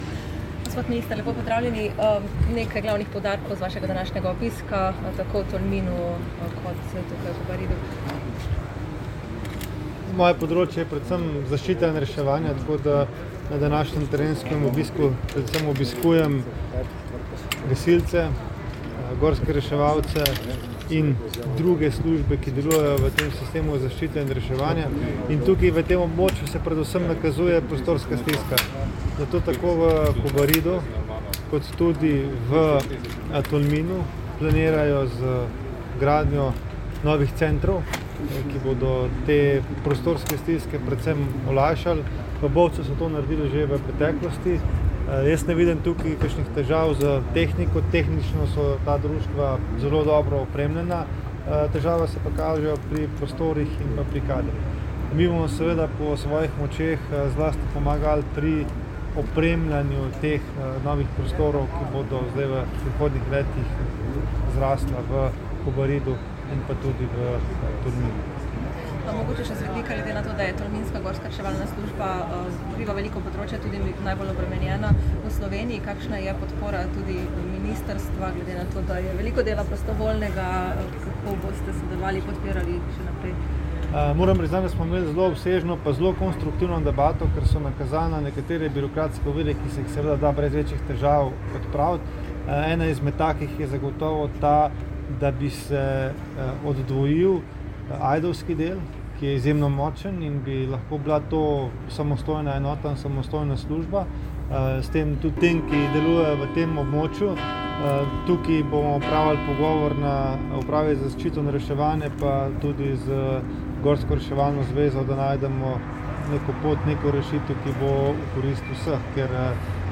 Zvočni posnetek izjave ministra Mateja Tonina (izjava je povzeta v sporočilu za javnost)